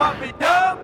SouthSide Chant (4).wav